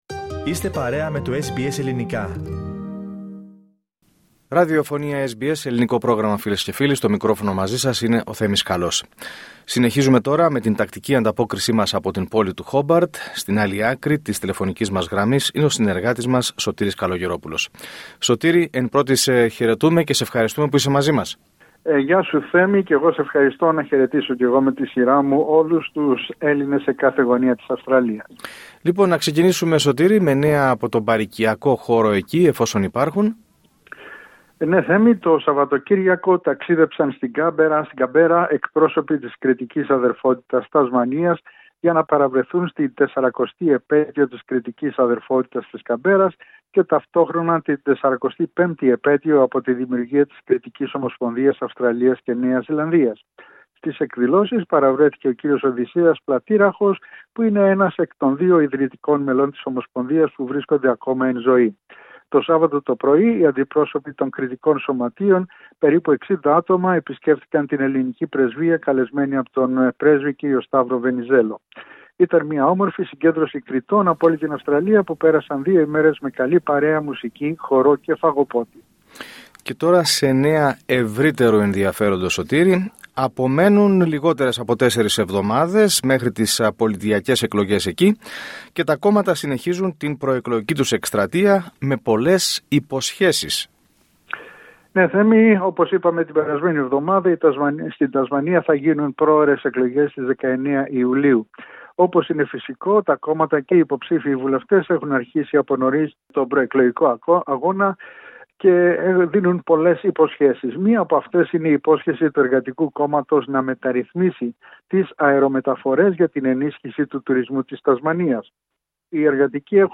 Ακούστε ολόκληρη την ανταπόκριση από την Τασμανία, πατώντας PLAY δίπλα από την κεντρική φωτογραφία.